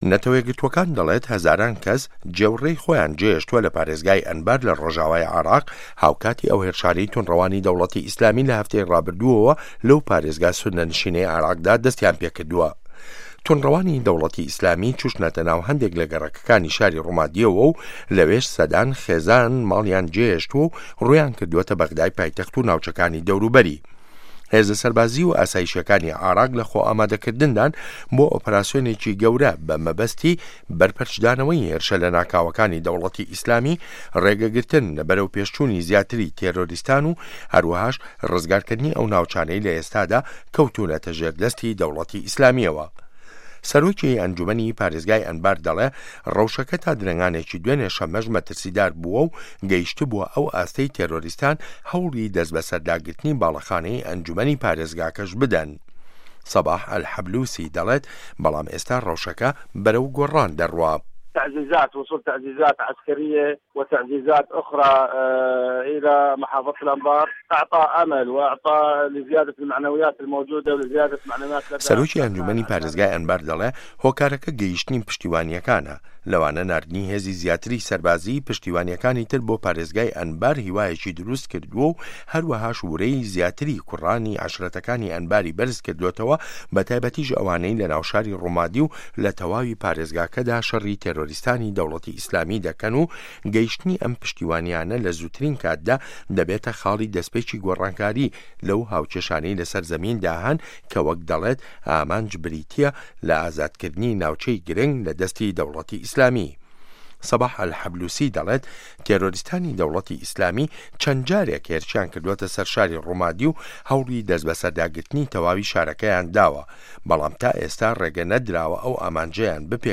ڕاپۆرت له‌سه‌ر بنچینه‌ی لێدوانه‌کانی سه‌باح ئه‌لحه‌لبوسی